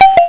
sounds / clicks / keybutt.wav